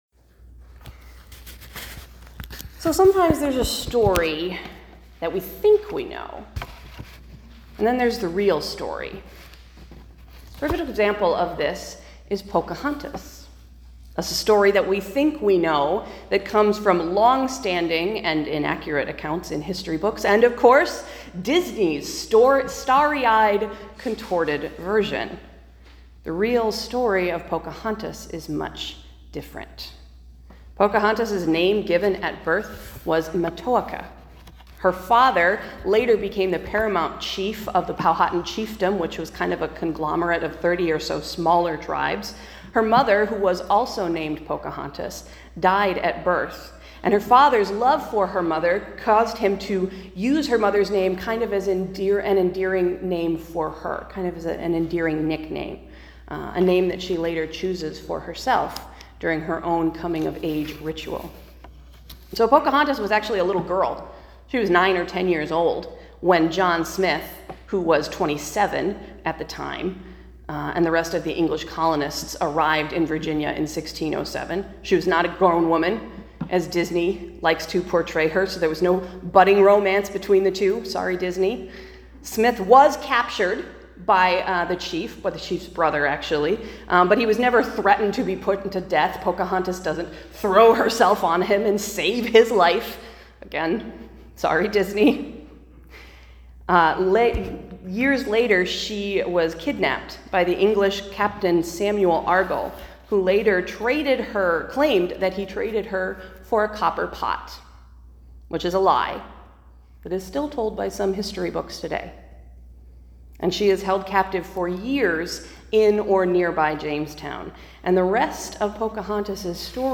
We were having technical trouble with our streaming equipment this week, so I recorded an audio version of the sermon instead: